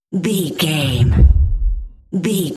Dramatic hit very deep trailer
Sound Effects
Atonal
heavy
intense
dark
aggressive